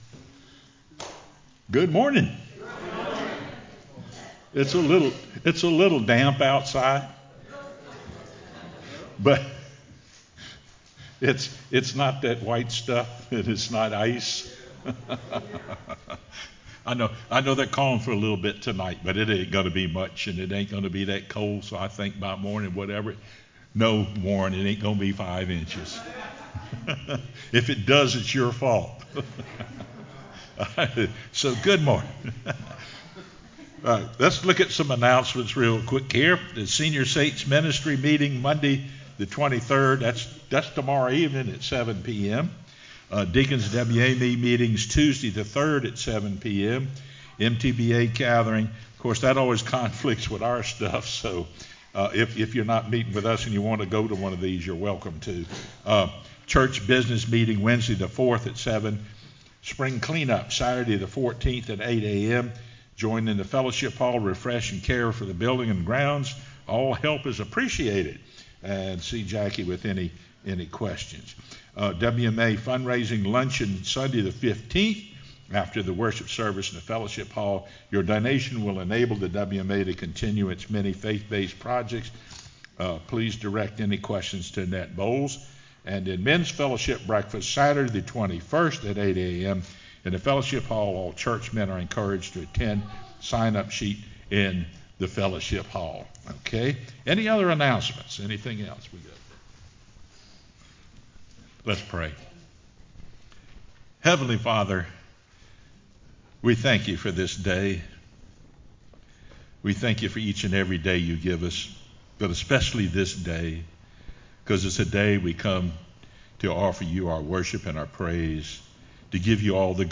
sermonFeb22-CD.mp3